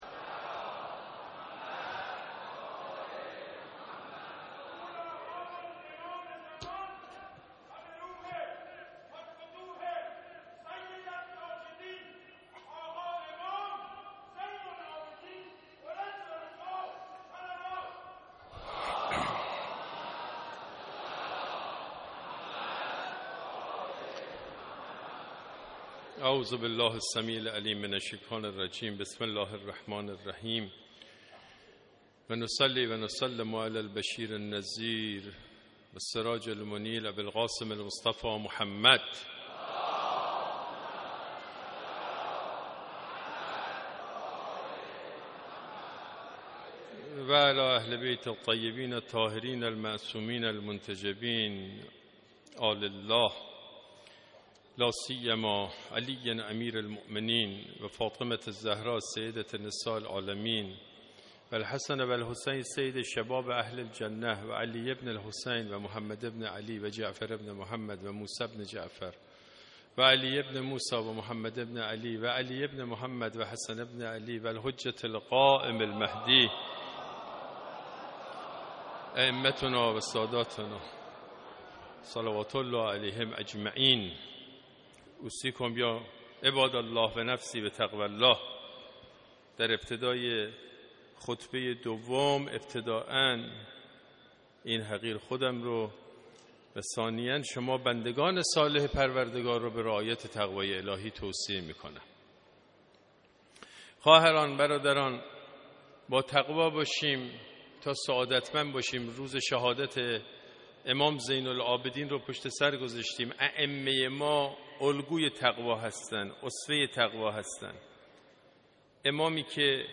خطبه دوم